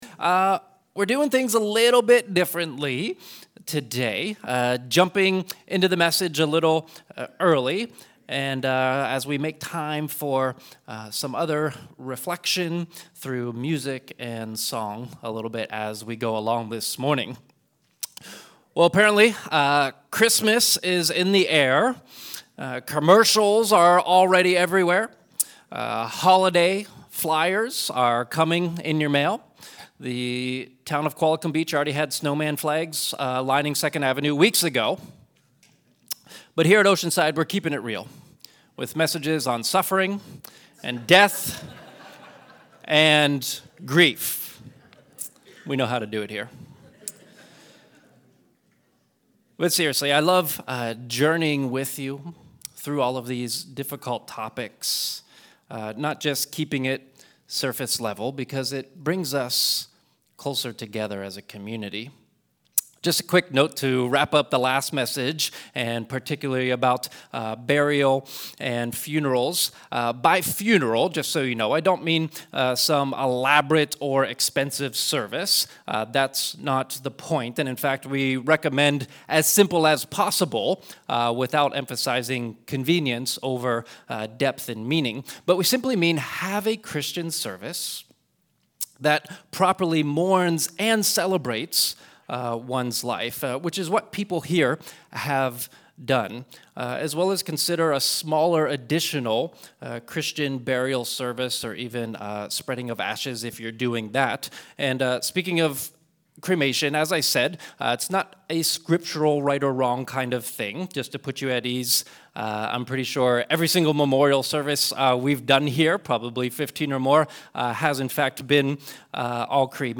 We conclude our Death & Grief series with a special message on Grief & Grieving Well, accompanied by song.